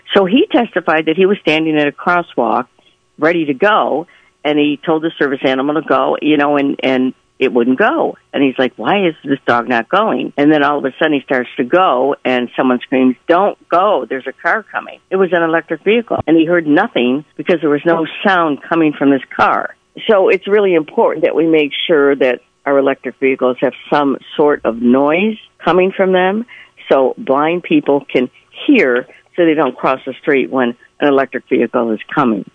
The full conversation with State Representative Schmaltz, along with other recent guest interviews, is available to listen to and download at the link below.